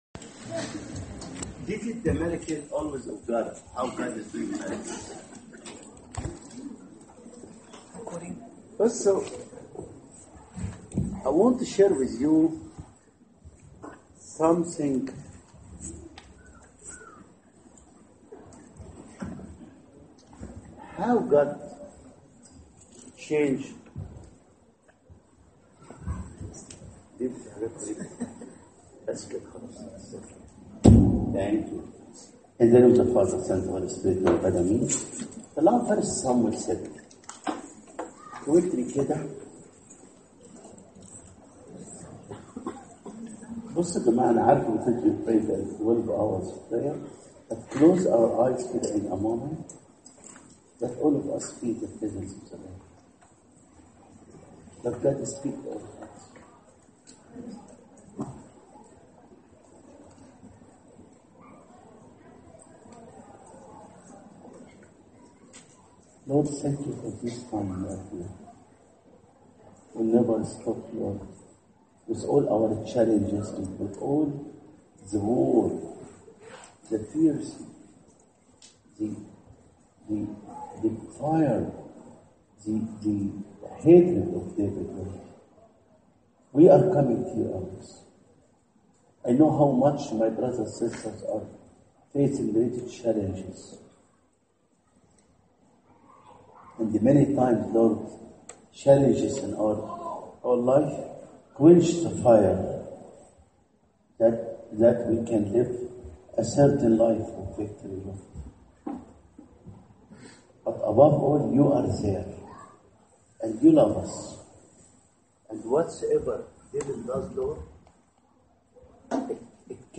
Bible Text: 1 Samuel 7 | Preacher: H.G. Bishop Boulos | Series: Saint Mark’s 2014 Summer Youth Camp | H.G. Bishop Boulos of Africa!!